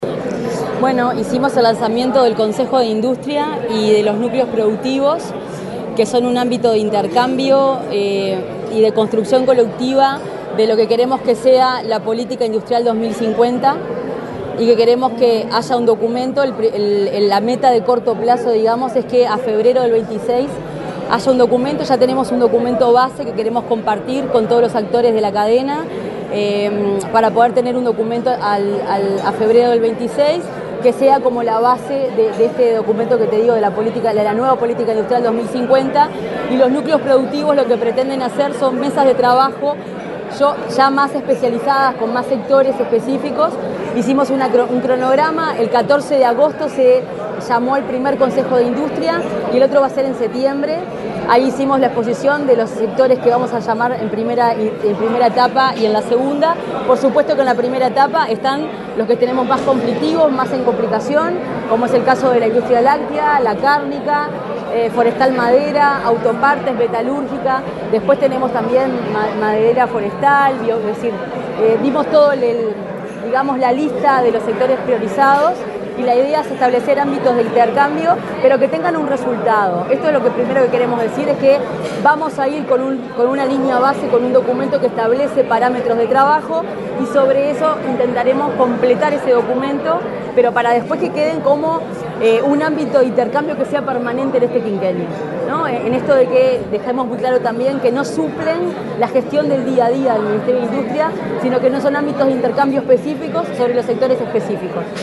Declaraciones de la ministra de Industria, Energía y Minería, Fernanda Cardona
Declaraciones de la ministra de Industria, Energía y Minería, Fernanda Cardona 29/07/2025 Compartir Facebook X Copiar enlace WhatsApp LinkedIn Tras el lanzamiento del Consejo de Industria y los Núcleos Productivos, la ministra de Industria, Energía y Minería, Fernanda Cardona, dialogó con la prensa.